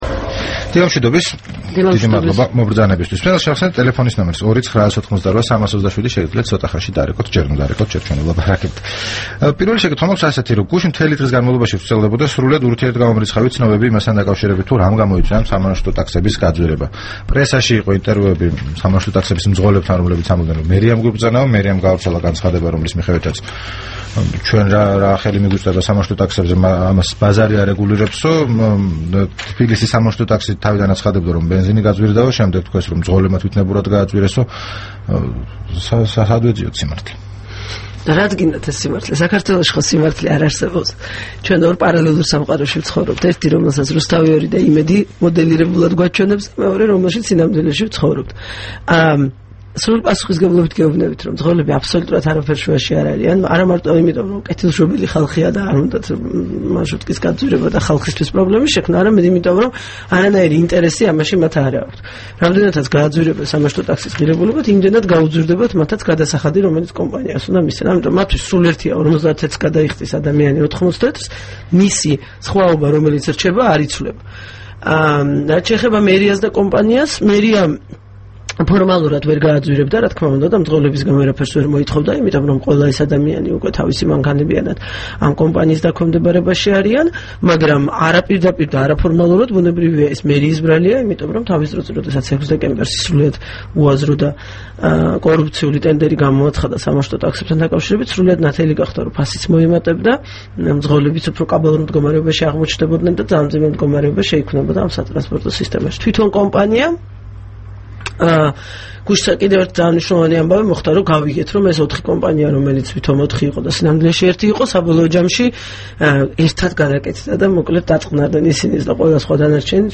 რადიო თავისუფლების თბილისის სტუდიაში სტუმრად იყო საკრებულოს წევრი, რესპუბლიკელი თინა ხიდაშელი.